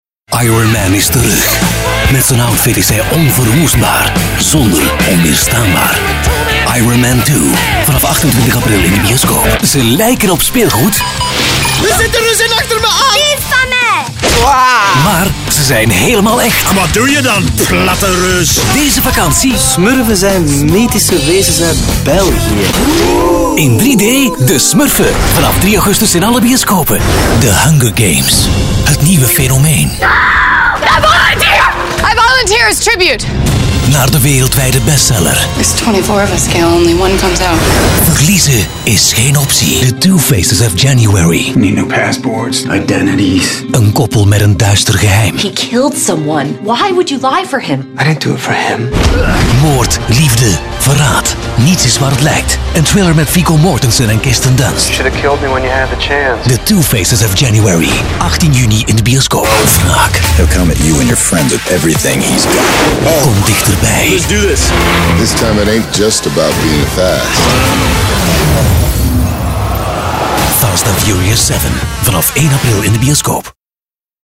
Allround Flemish voice over, wide range of styles
Sprechprobe: Sonstiges (Muttersprache):